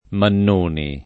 [ mann 1 ni ]